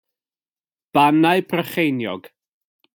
The Brecon Beacons (Welsh: Bannau Brycheiniog; [ˈbanai̯ brəˈχei̯njɔɡ]
Bannau_Brycheiniog.wav.mp3